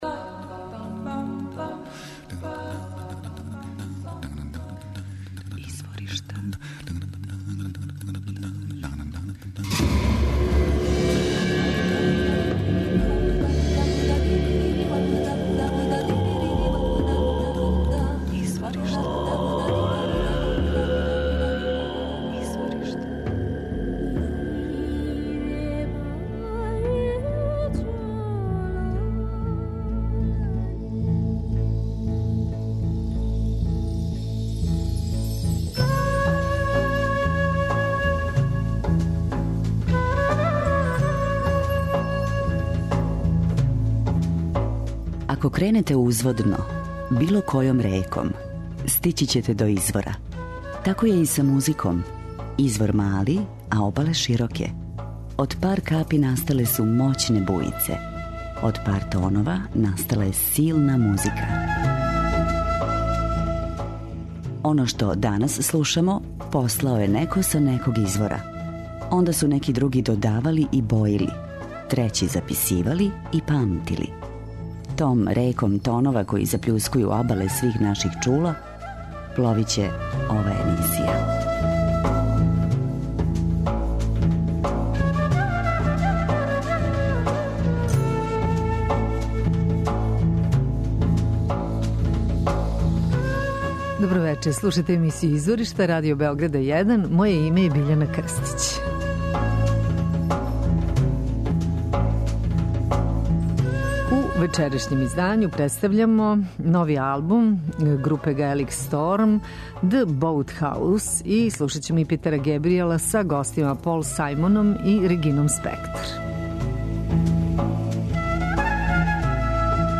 келтске групе